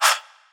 Pyrex Vox (15).wav